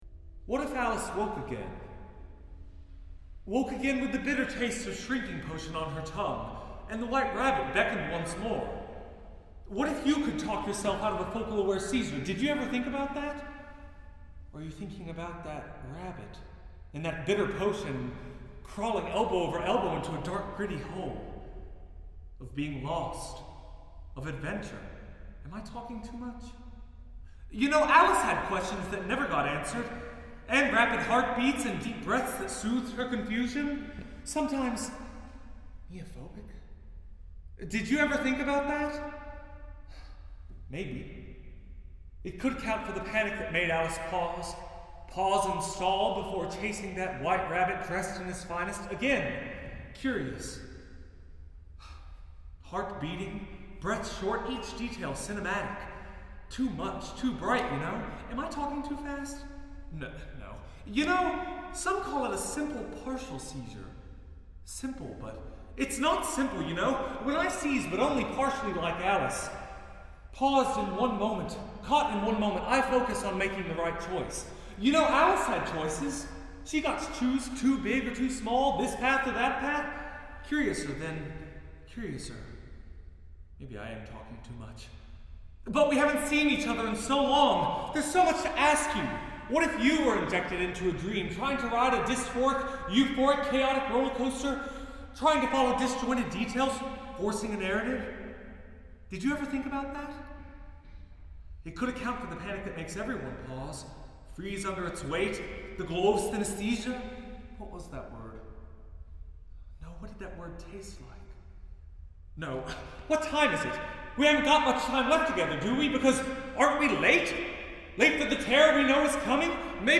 listen to the poem, vocally performed